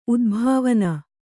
♪ udbhāvana